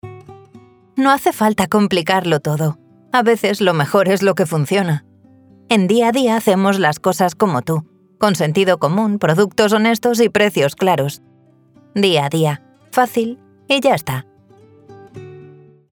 Grabaciones de Alta Calidad con Estudio Propio
Mi voz se encuentra en el rango de mujer adulta/joven y se adapta a diferentes estilos: cálida y cercana, sensual, agresiva, tímida, divertida… lo que necesites para tu proyecto.
Demo reel de publicidad voz natural
Cabina insonorizada